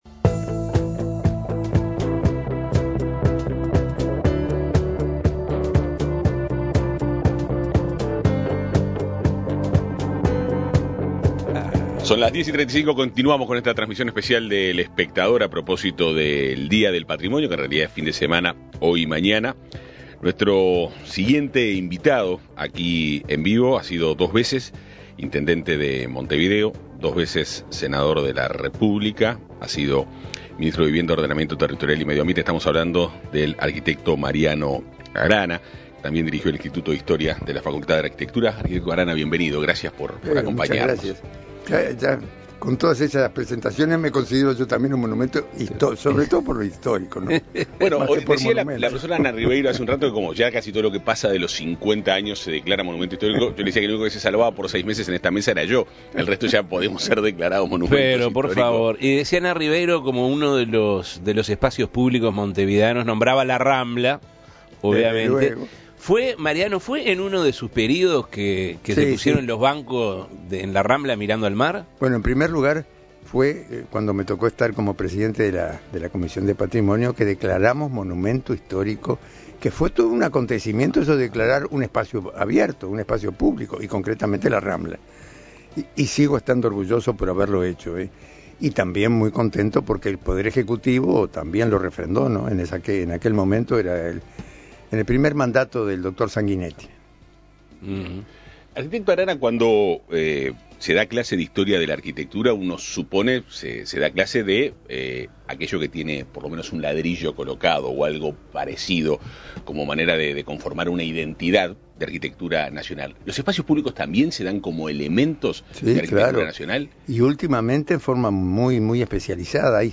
El arquitecto y exdirector del Instituto de Historia de la Arquitectura Mariano Arana dialogó con El Espectador a propósito del Día del Patrimonio, su experiencia en la gestión del Ministerio de Vivienda, Ordenamiento Territorial y Medio Ambiente, cargo que desempeñó hasta el 2008, durante la gestión de Tabaréz Vázquez.
Más conceptos, experiencias y detalles de la entrevista que concedió el arquitecto Mariano Arana, aquí: